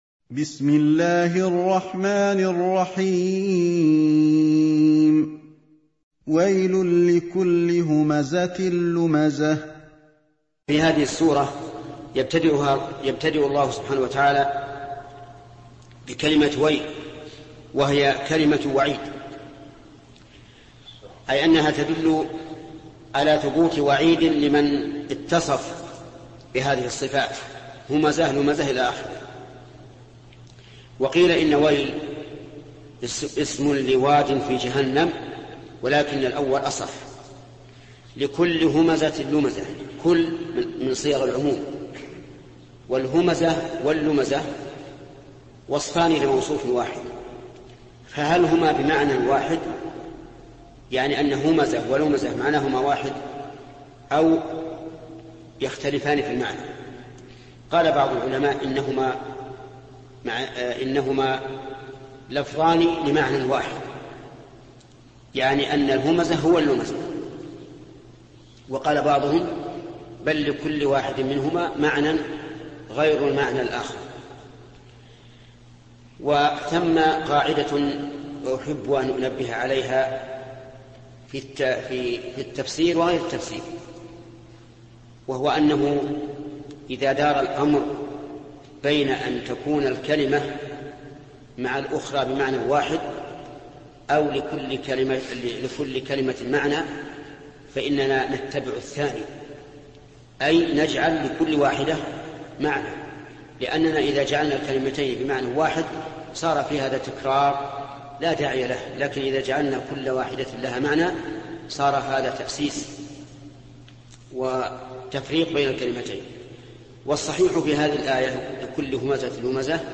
الدرس الثالث والعشرون: من قوله تفسير سورة الهمزة، إلى نهاية تفسير سورة قريش.